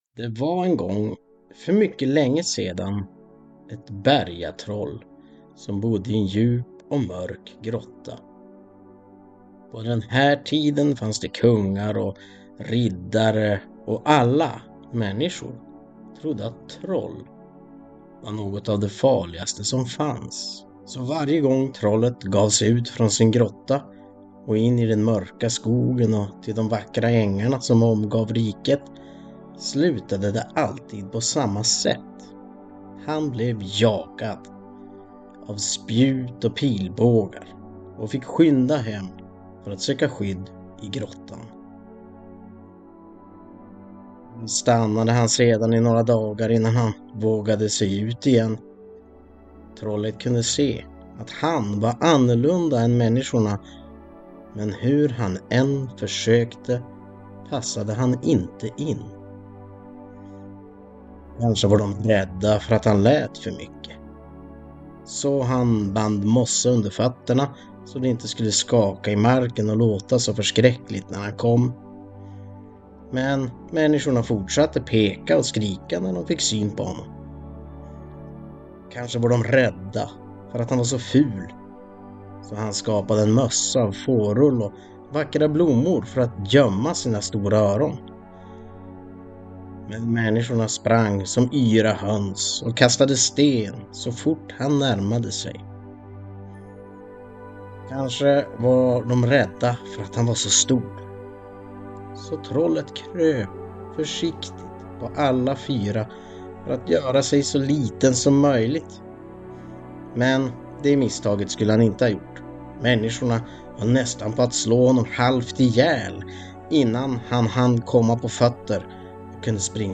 Godnattsaga: Draktrollet